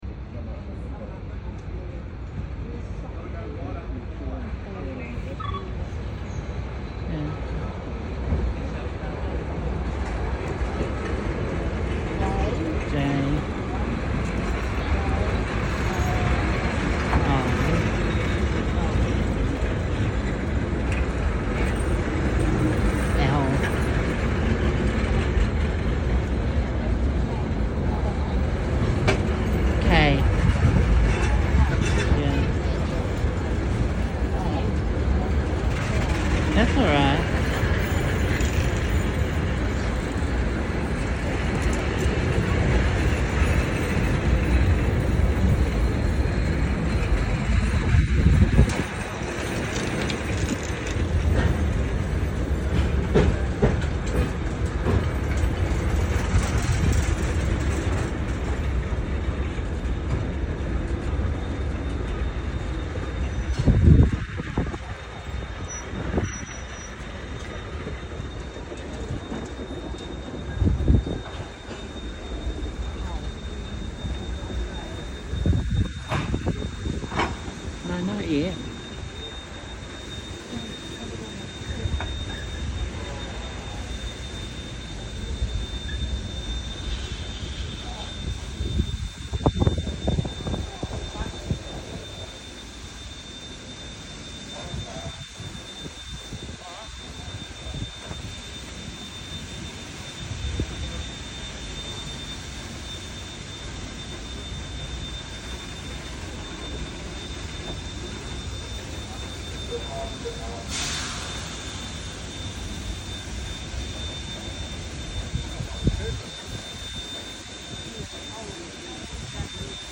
Steam train arriving in Auckland sound effects free download